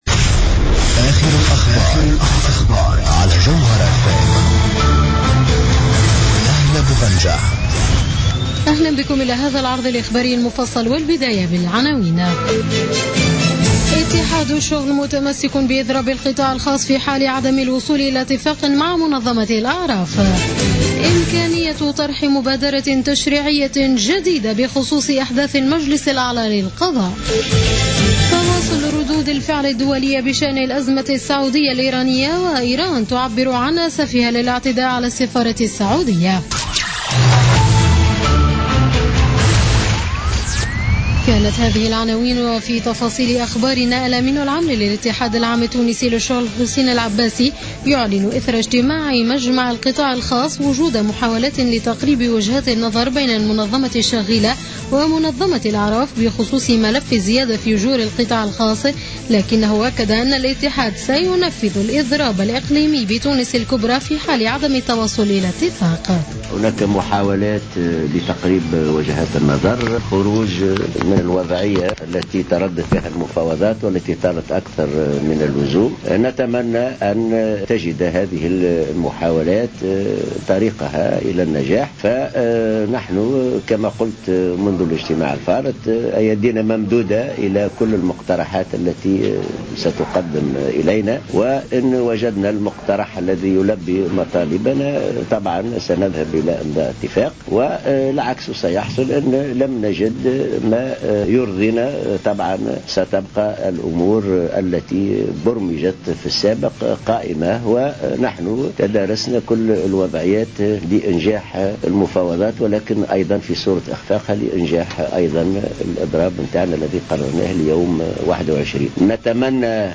نشرة أخبار منتصف الليل ليوم الثلاثاء 5 جانفي 2015